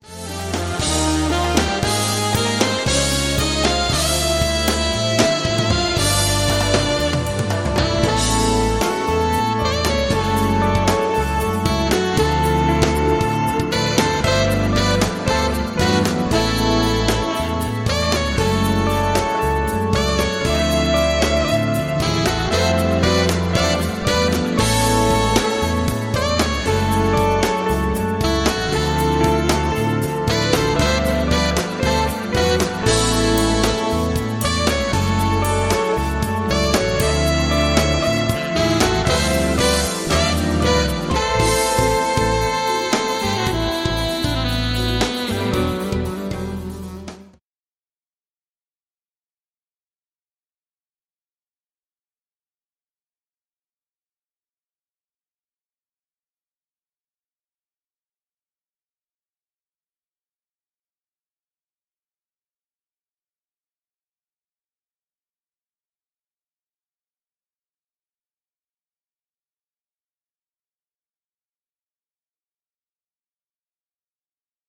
guitar, sax, pan flute and harmonica